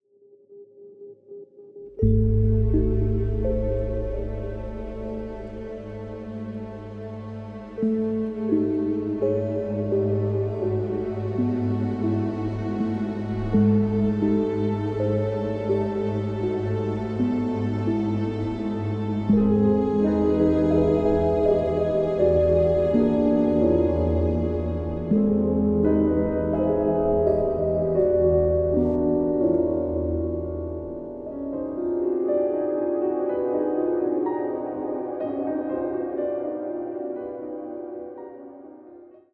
blending electronic sounds and acoustic strings